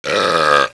burp.aif